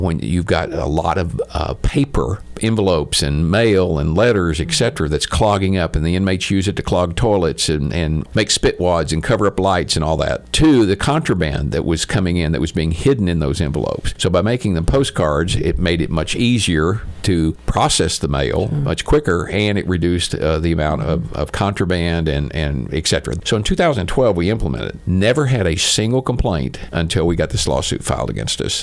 Ahead of the Baxter County Quorum Court meeting tonight, which will seek to appropriate fees associated with the ruling against the jail’s mail policy, Sheriff John Montgomery spoke with KTLO News to discuss the past and the future of the policy.
The Sheriff explains why the policy was originally implemented.